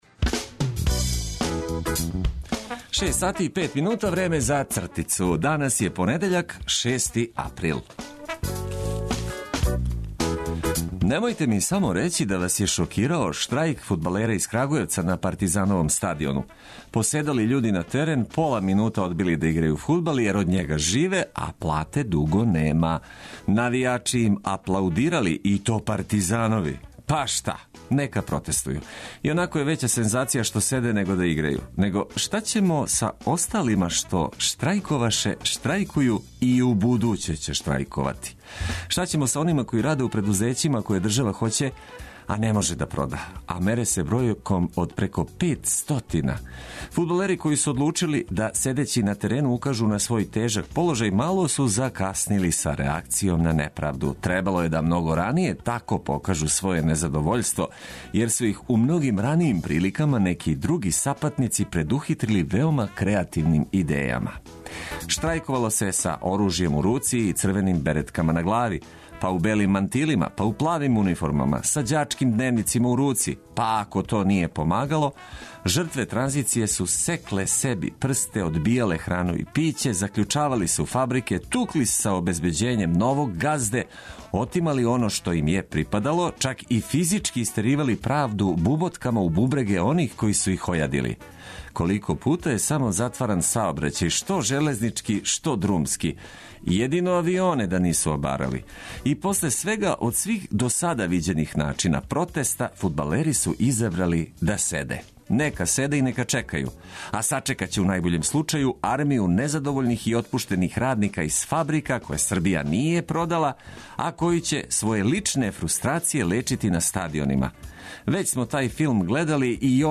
Будићемо вас и припремати за почетак радне недеље у нади да ће вам од користи бити информације које објавимо уз квалитетну музику одабрану да убрза ритам устајања.